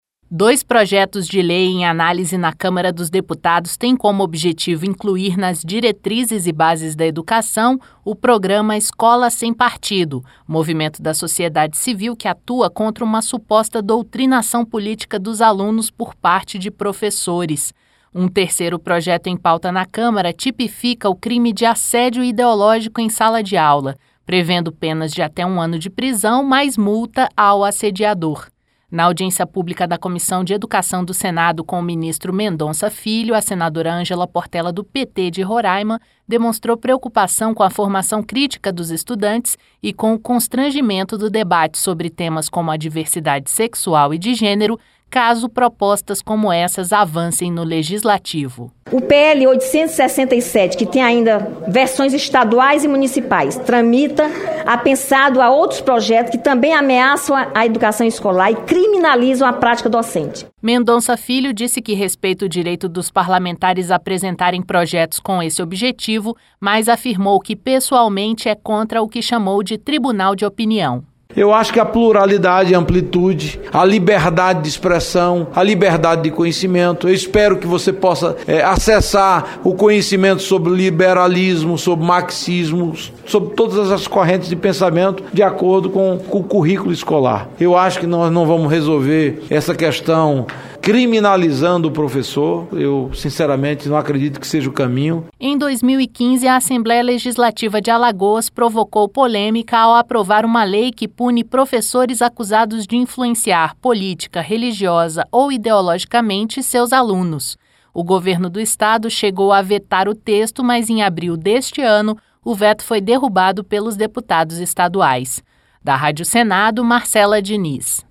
Apesar das críticas ao que chamou de “tribunal de opinião”, Mendonça Filho disse que respeita a opinião dos parlamentares que apoiam a ideia. O ministro participou de audiência pública nesta quarta-feira (6) na Comissão de Educação, Cultura e Esporte (CE).